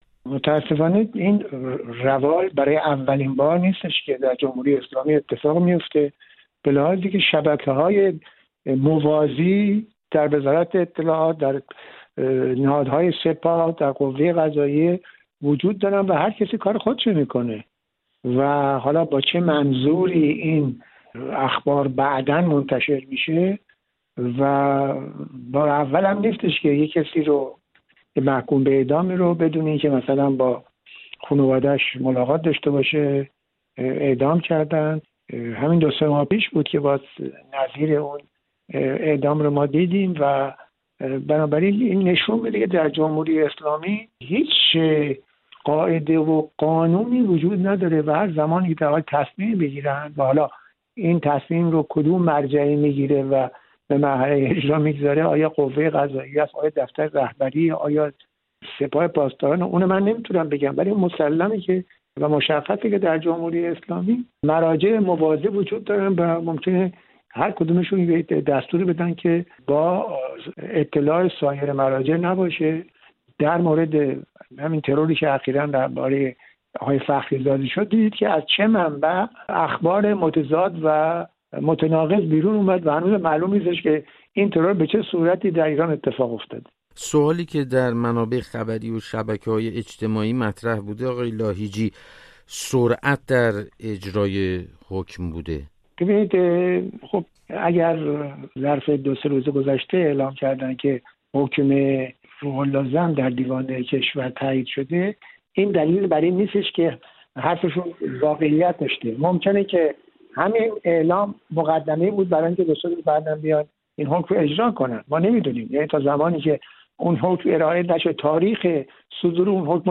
عبدالکریم لاهیجی، رئیس افتخاری و دائمی فدراسیون بین المللی جامعه های دفاع از حقوق بشر در پاریس به رادیو فردا میگوید: